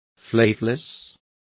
Complete with pronunciation of the translation of flavourless.